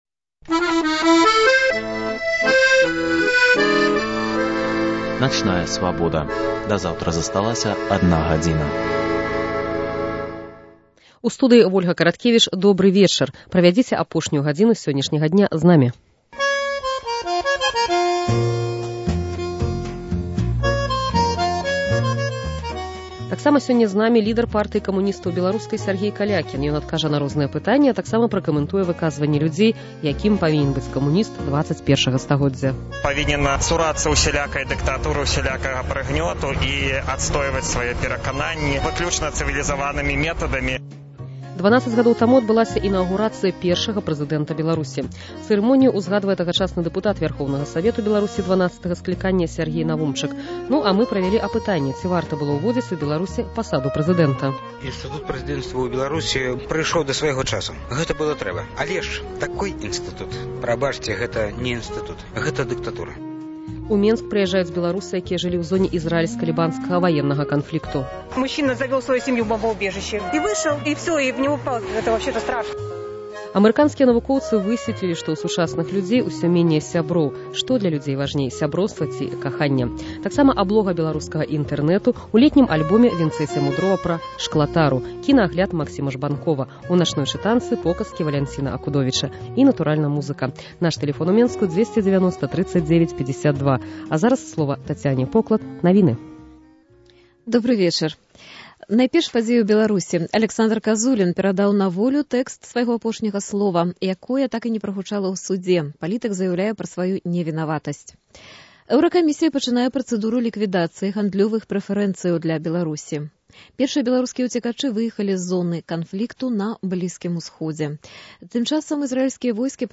Рэпартаж з аэрапорту "Менск-2".